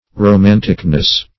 Search Result for " romanticness" : The Collaborative International Dictionary of English v.0.48: Romanticness \Ro*man"tic*ness\, n. The state or quality of being romantic; widness; fancifulness.
romanticness.mp3